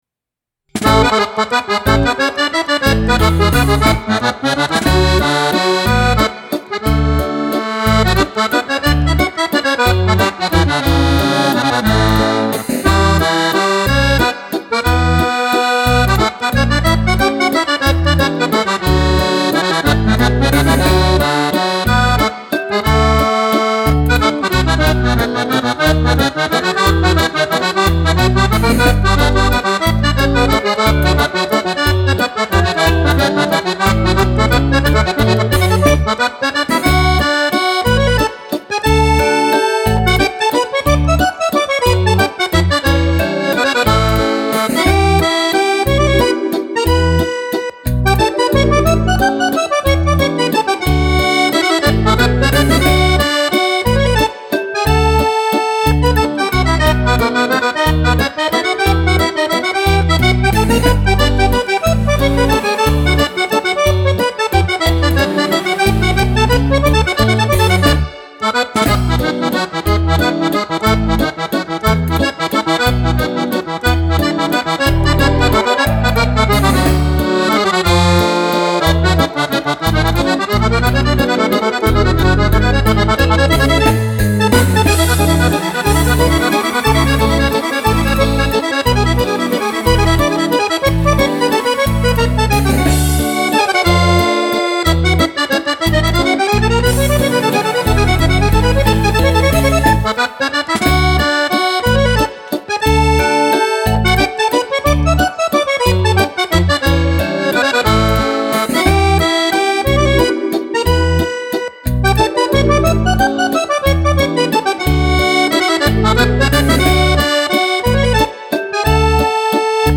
Valzer
Gran valzer